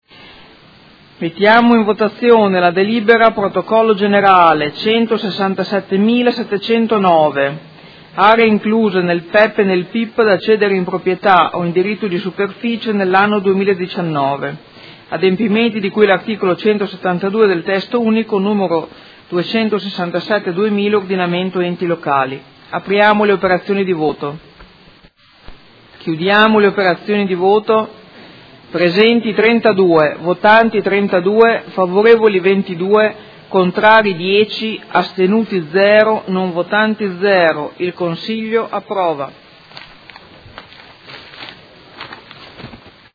Presidente — Sito Audio Consiglio Comunale
Seduta del 20/12/2018.